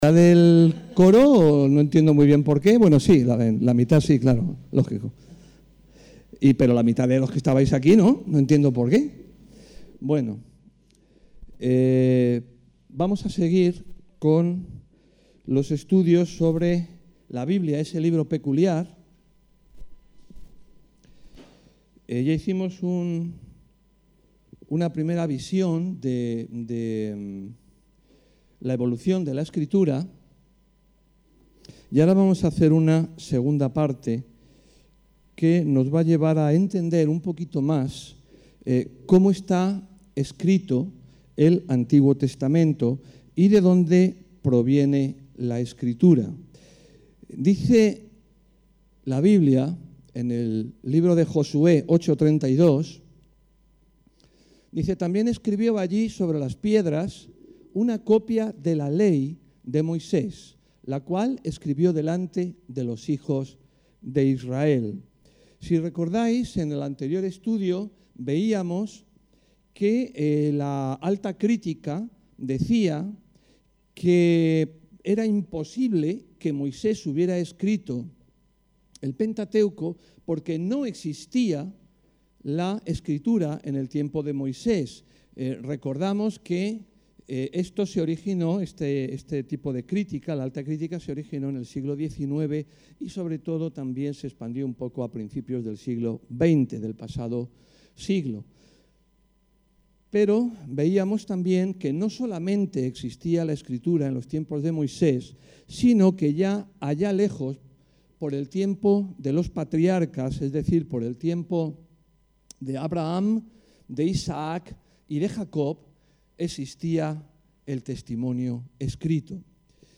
El texto de la predicación se puede descargar AQUÍ: biblia-03-origen-escritura-ii